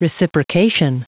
Prononciation du mot reciprocation en anglais (fichier audio)
Prononciation du mot : reciprocation